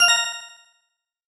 snd_coin_ch1.wav